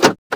vehicleLock.wav